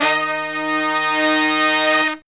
LONG HIT08-L.wav